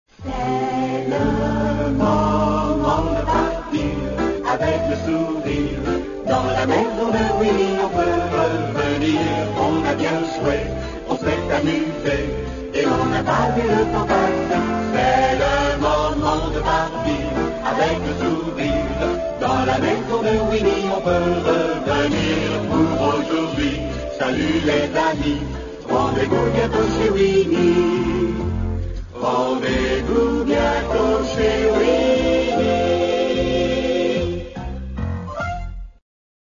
Version française du générique de fin de la série.